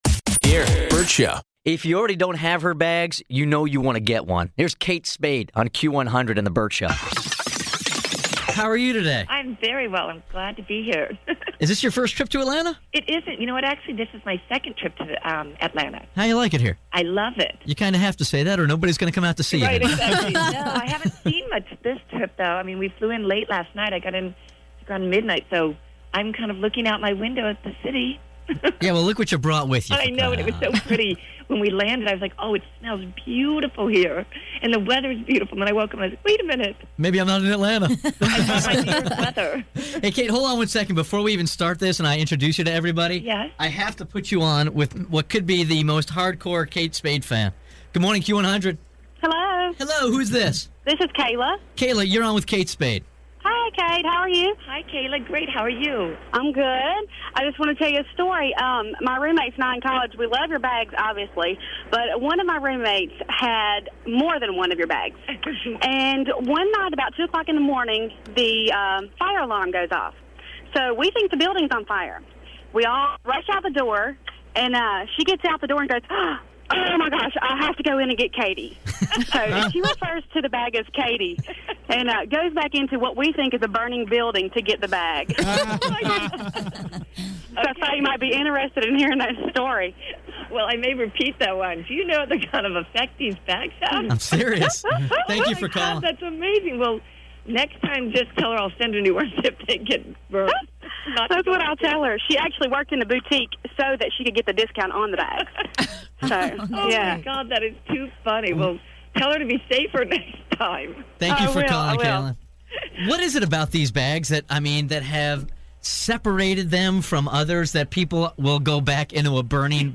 Vault: Interview - Kate Spade